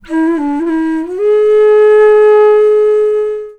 FLUTE-B03 -R.wav